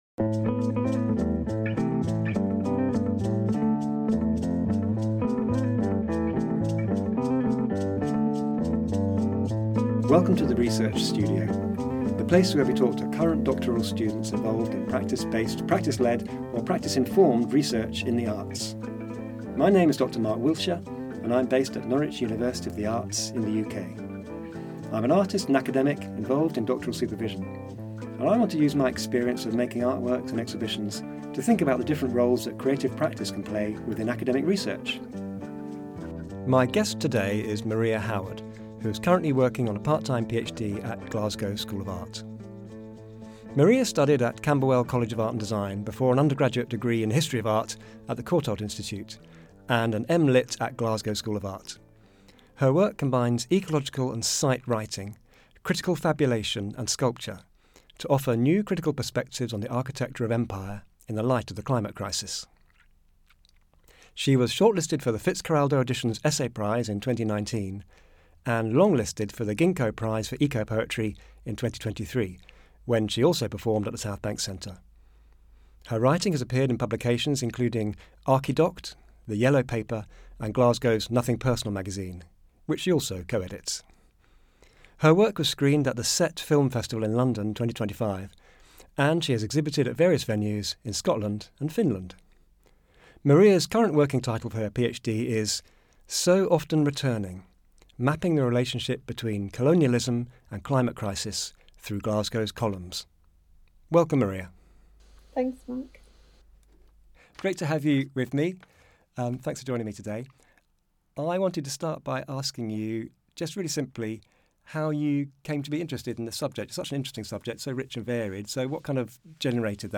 In this episode’s interview we discuss the practice of site-writing, colonialism, Empire and the climate crisis.